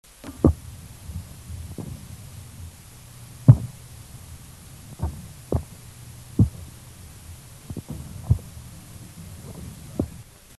Sound produced yes, active sound production
Type of sound produced thumps, knocks, booms, escape sounds
Sound production organ swim bladder
Behavioural context loud booms from large specimen, weaker thumps from small fish under duress (manual stimulation), escape sounds & low knocks with electric stimulation
Remark recording amplified by 6 dB